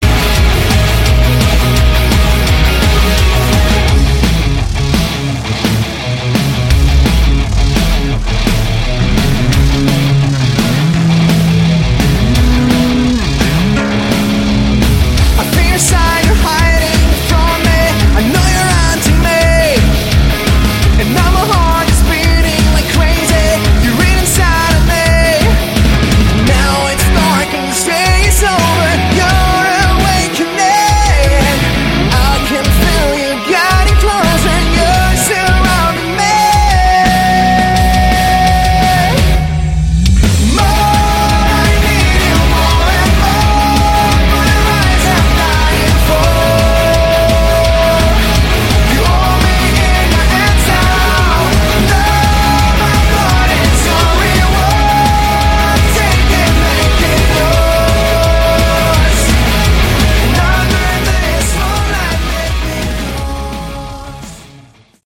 Category: Sleaze Glam
vocals
bass
drums
guitar